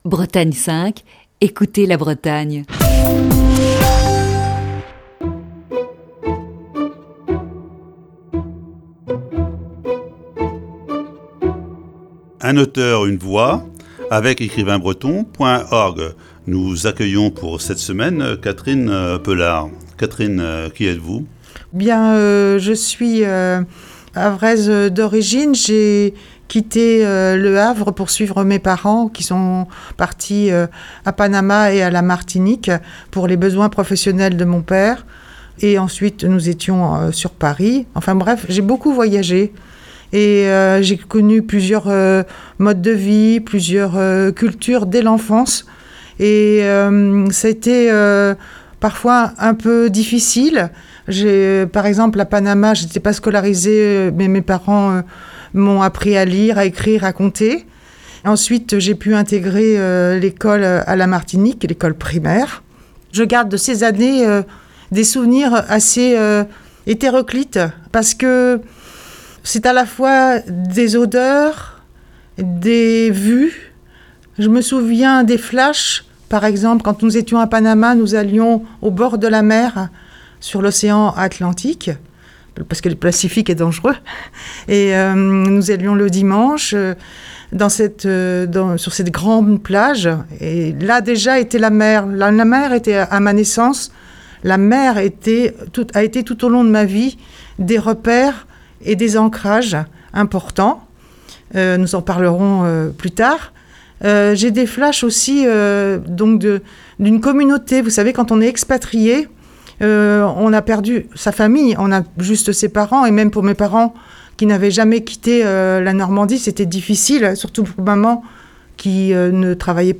Voici ce lundi, la première partie de cette série d'entretiens.